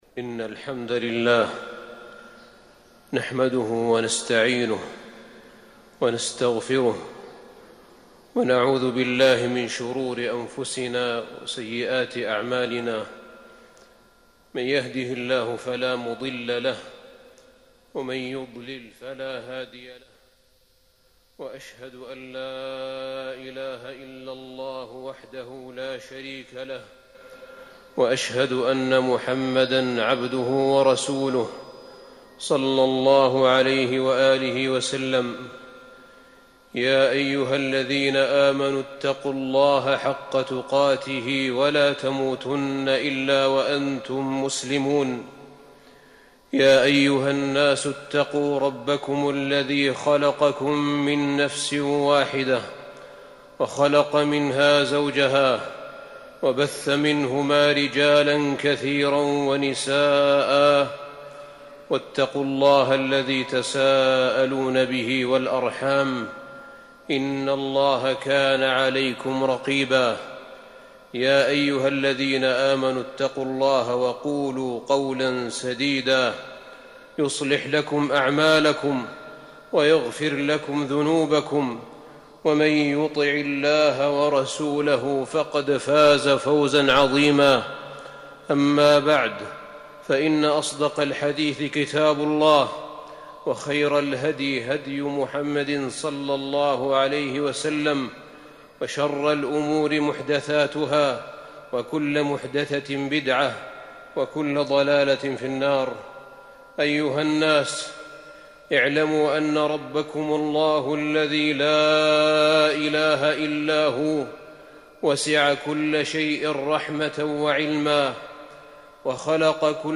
تاريخ النشر ٢٥ رجب ١٤٤١ هـ المكان: المسجد النبوي الشيخ: فضيلة الشيخ أحمد بن طالب بن حميد فضيلة الشيخ أحمد بن طالب بن حميد الله لطيفٌ بعباده